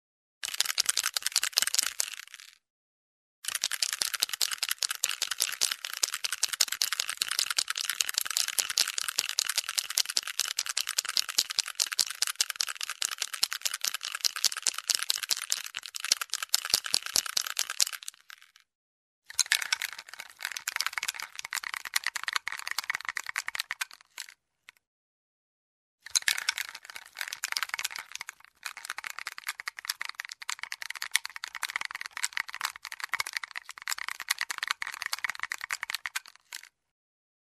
Звуки парикмахерской
Трясут спрей с металлическим шариком внутри